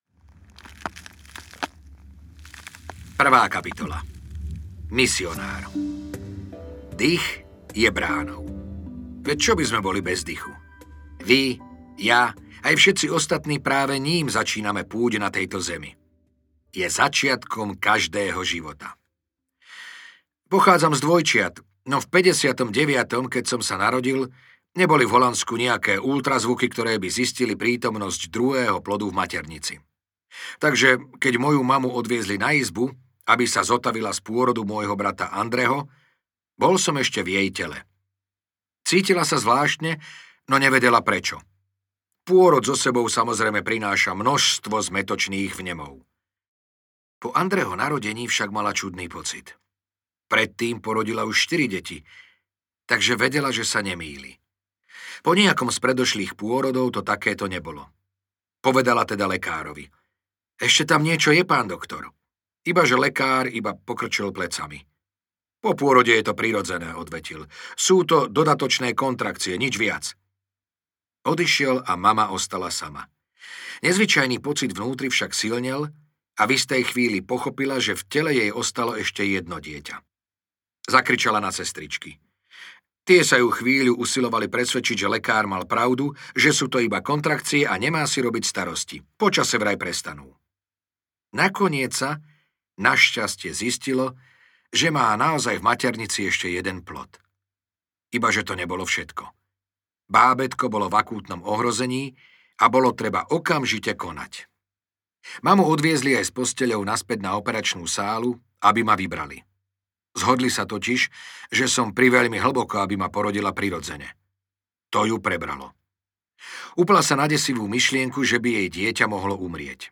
Audiokniha Metóda Wima Hofa | ProgresGuru